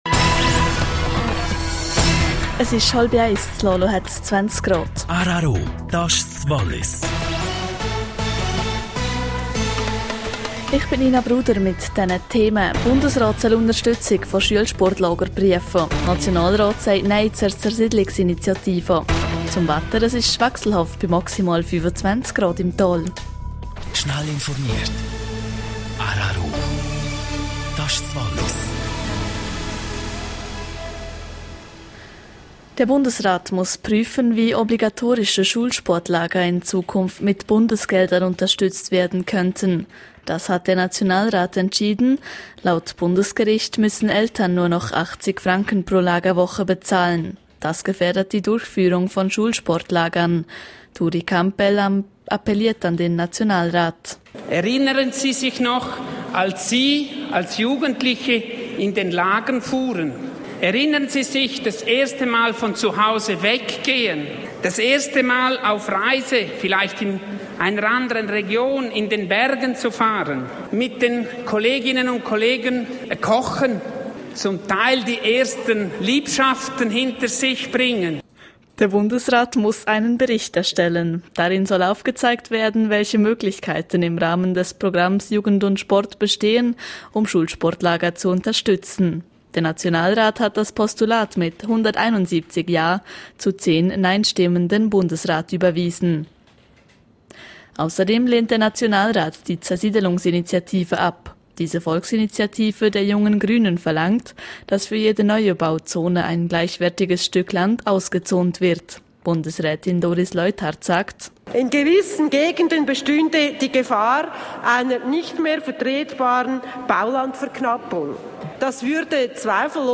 12:30 Uhr Nachrichten (4.04MB)